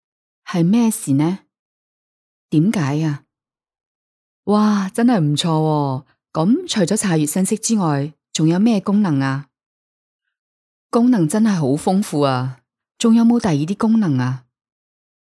女声 Female Voice-公司名
女粤DY 粤语女声 馨瑷月子（普话）